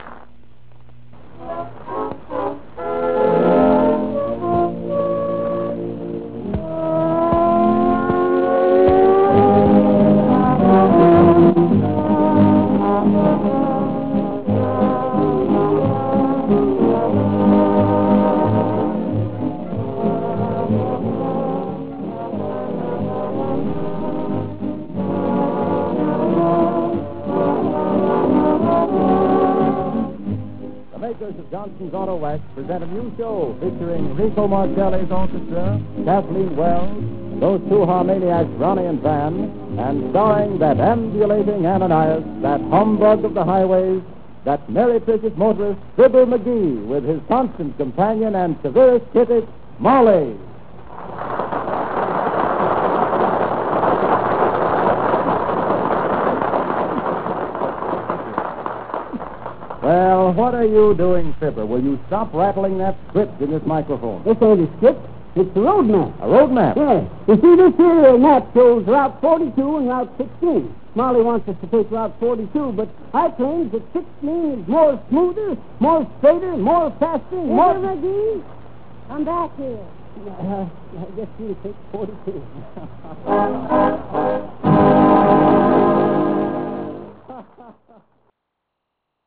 RealAudio 2.0 clip:The opening of the first Fibber and Molly show.